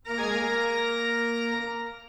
BWV 565 · A mordente
en la grabación en vivo
PCM WAV, 16 bit, 44.1 kHz, estéreo
A vista general reconoces la estructura: el ataque de la nota, su sustain y el silencio que sigue.
A5 · 880 Hz · armónicos cada 880 Hz